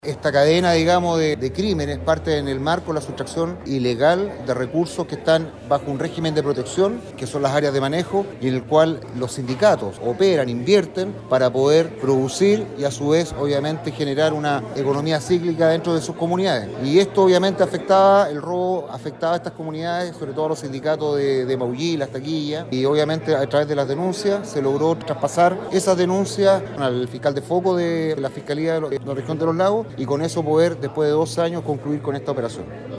El gobernador marítimo de Puerto Montt, comandante Dinson Baack, dijo que esto se da en un trabajo mancomunado entre personal de la Armada con los trabajadores de mar del sector.